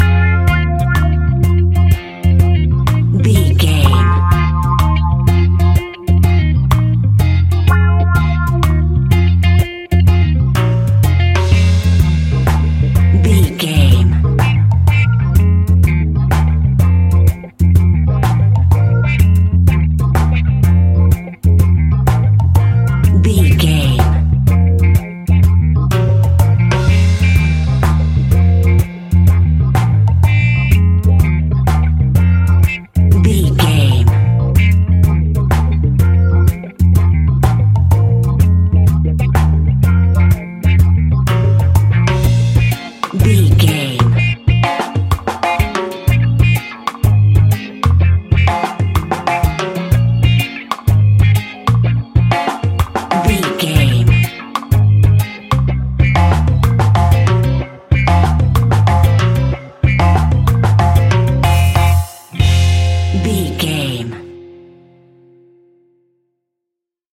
Classic reggae music with that skank bounce reggae feeling.
Aeolian/Minor
laid back
off beat
drums
skank guitar
hammond organ
percussion
horns